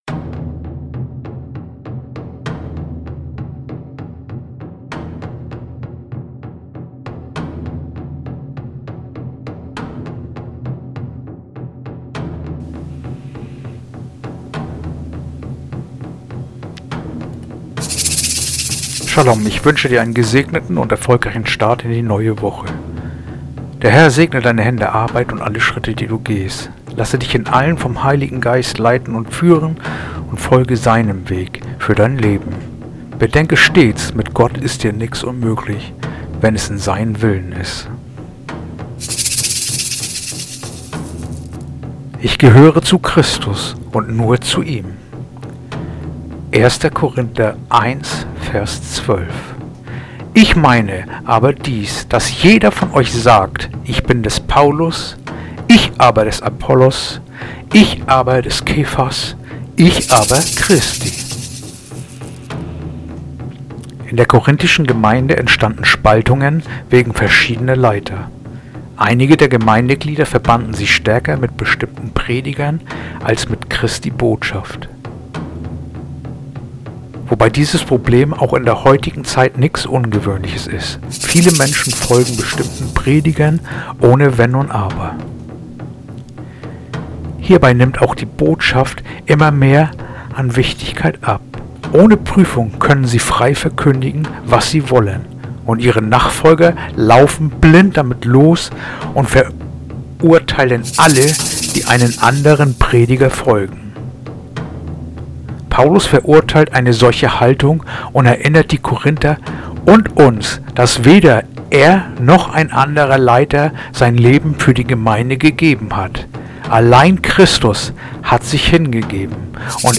heutige akustische Andacht